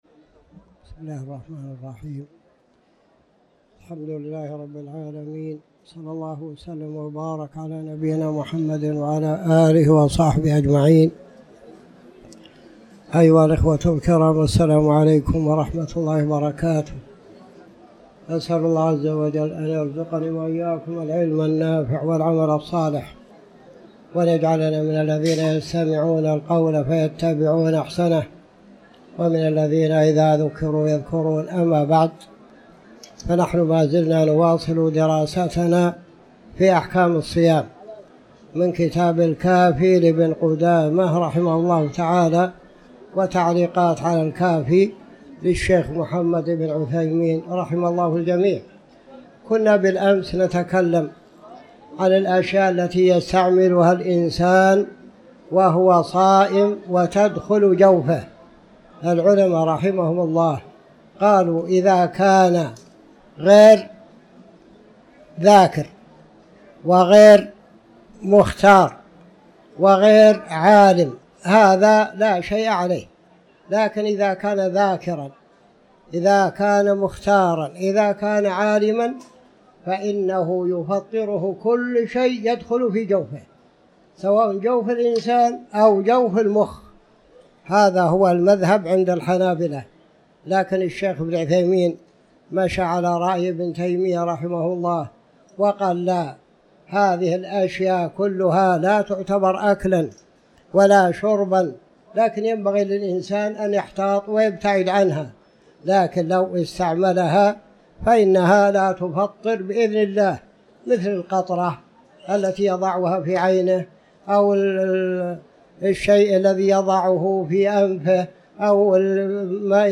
تاريخ النشر ١٠ رمضان ١٤٤٠ هـ المكان: المسجد الحرام الشيخ